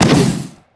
hazzard_fire_01.wav